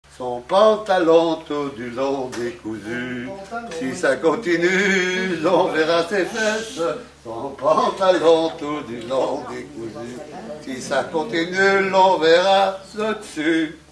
danse : scottish
Pièce musicale inédite